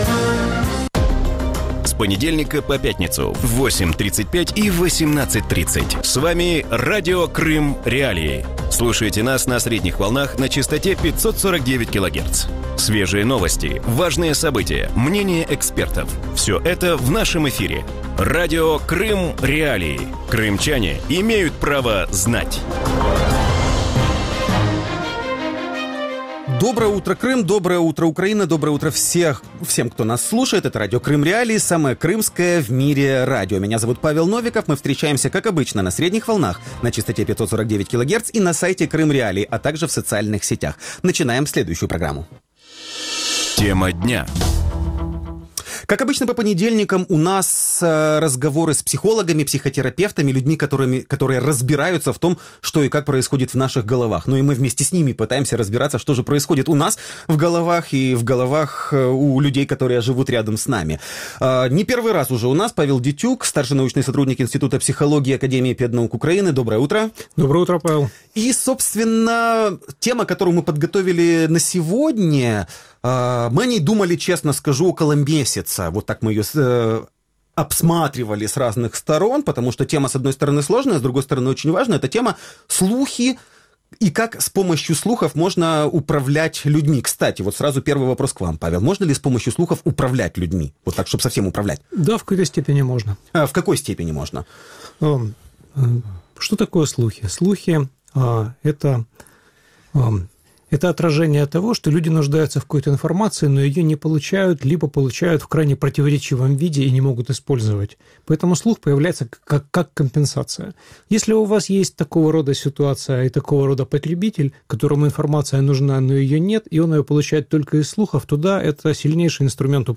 Жители Крыма могут бесплатно звонить в эфир